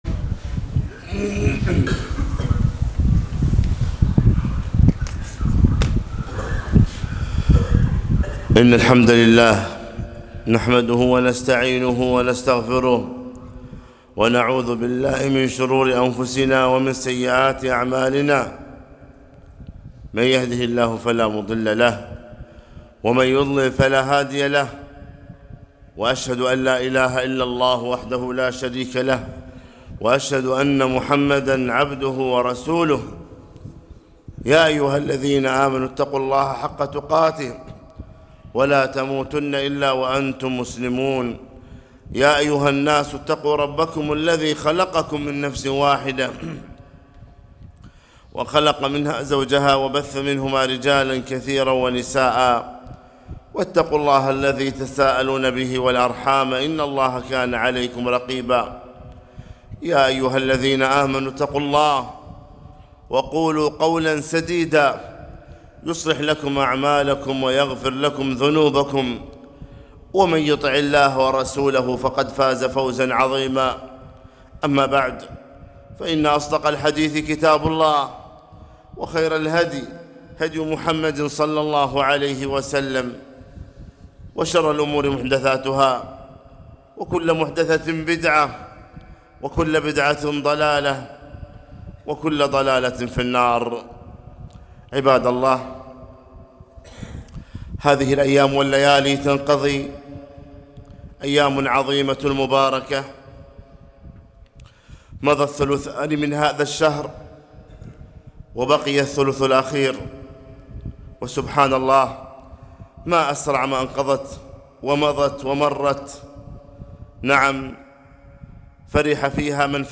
خطبة - إغتنموا العشر الأواخر